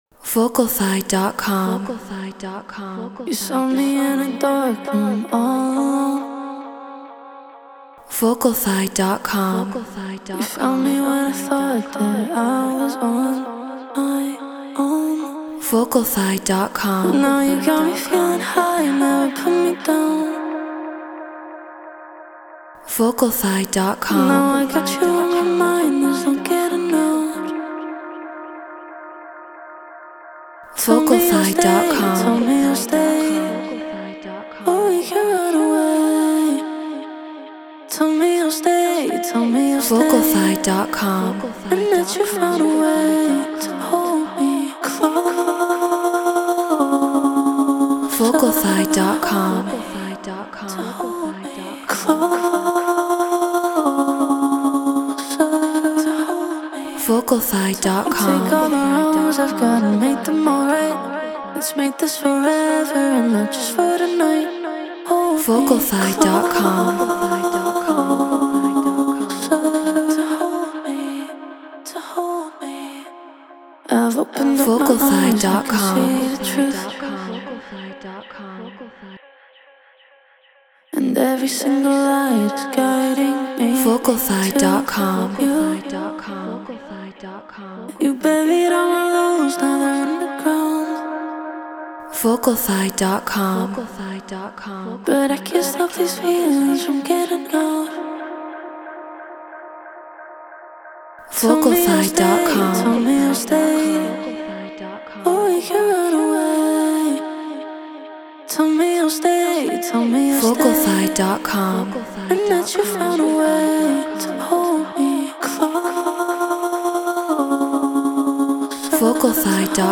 Garage 136 BPM A#min
Shure SM7B Apollo Solo Logic Pro Treated Room